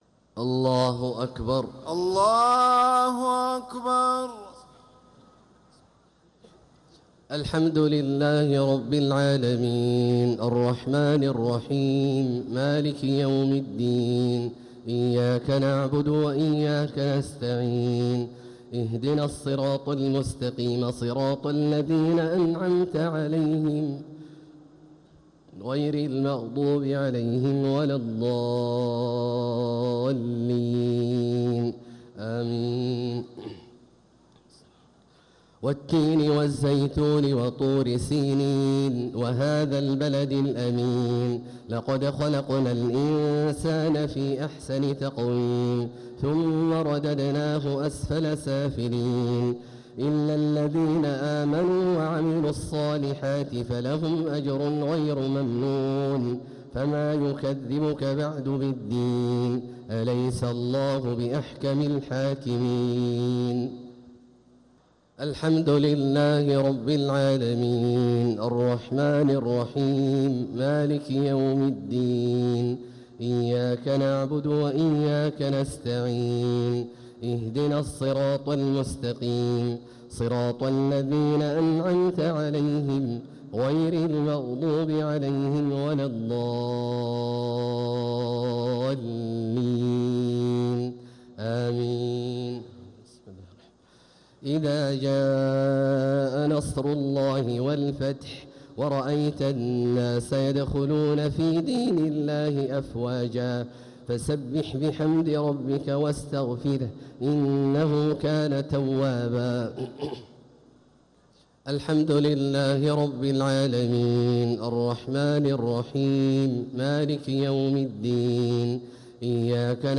صلاة الشفع و الوتر ليلة 5 رمضان 1447هـ | Witr 5th night Ramadan 1447H > تراويح الحرم المكي عام 1447 🕋 > التراويح - تلاوات الحرمين